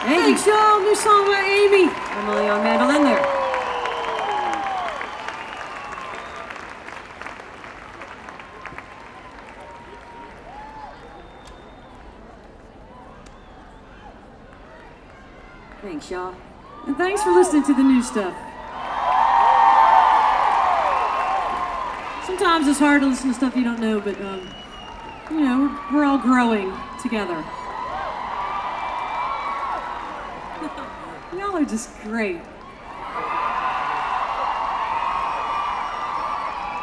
17. talking with the crowd (0:36)